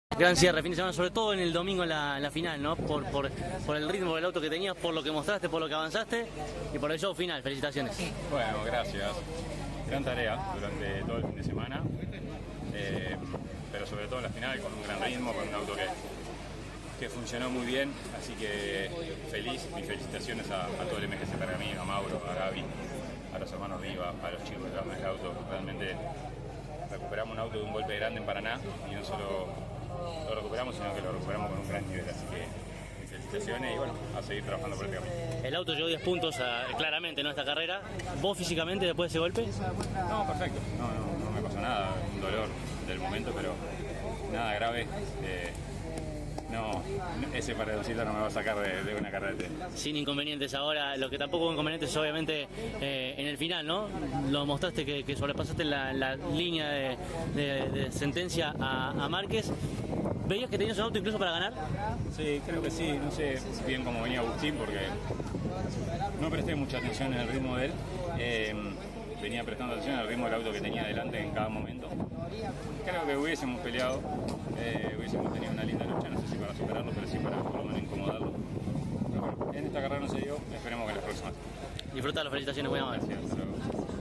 LA PALABRA DE LOS TRES DEL «ESTRADO DE HONOR» DE LA FINAL DE LA CLASE 3 DEL TN EN CÓRDOBA